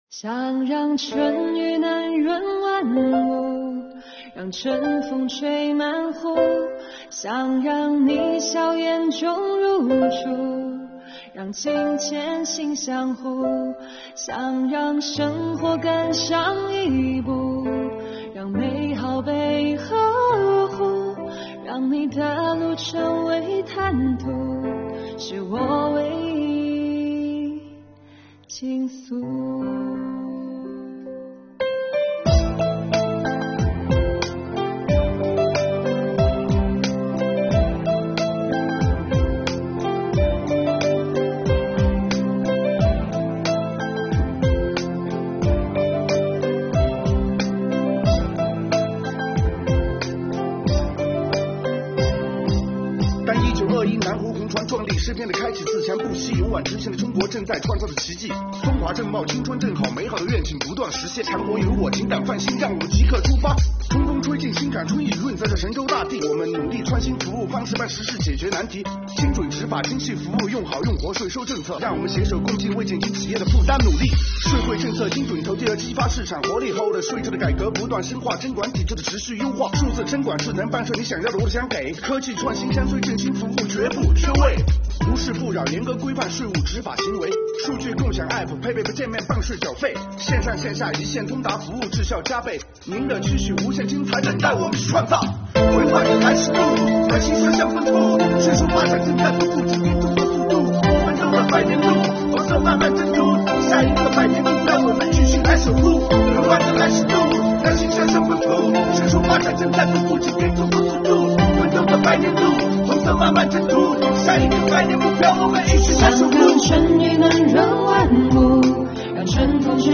就是这样一首歌，以简单明快的节奏，表达出了税务人与纳税人暖心双向奔赴的情感。它唱出了税务人投身改革的铮铮誓言，诉说了对纳税人缴费人的温暖情怀。